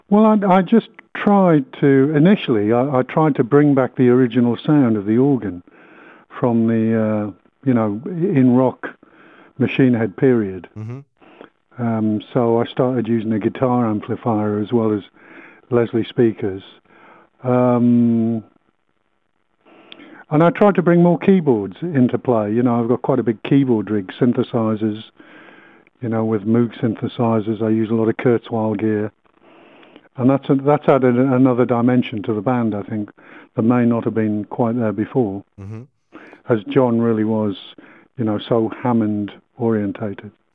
Don Airey, claviers de Deep Purple